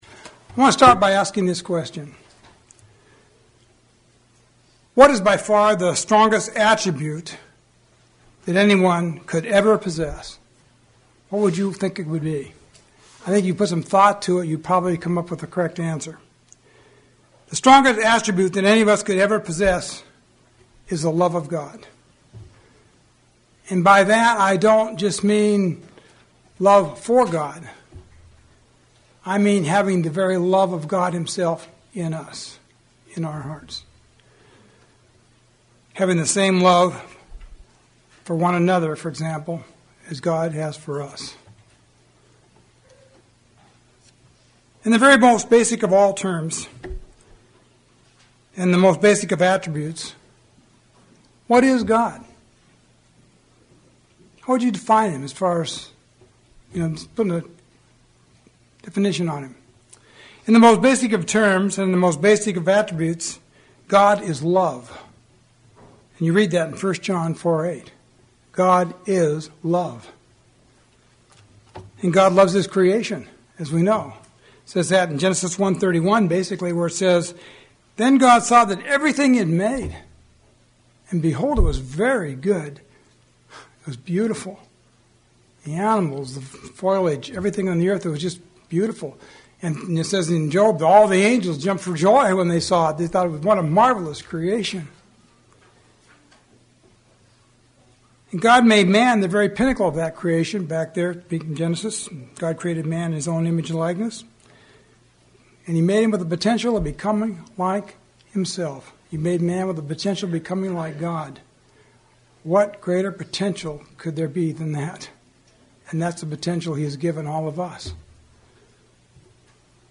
We must be like God and God is love. sermon Transcript This transcript was generated by AI and may contain errors.